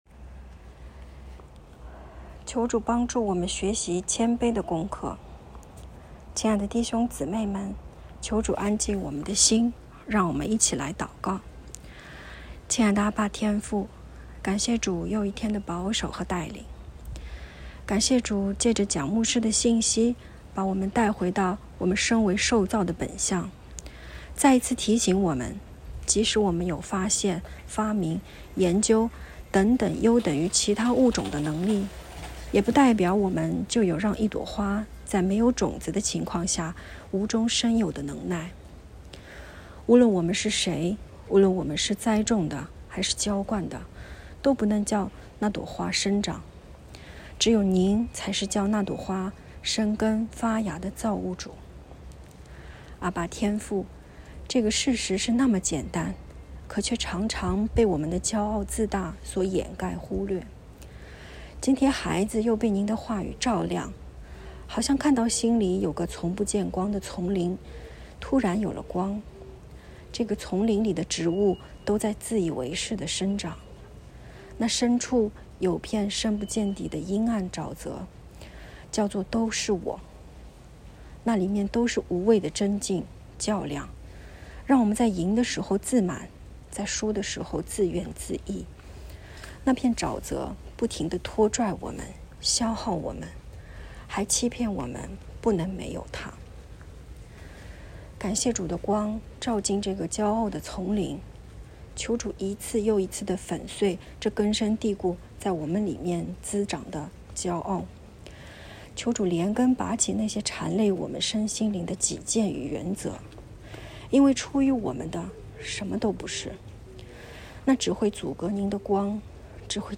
✨晚祷时间✨5月17日（周二）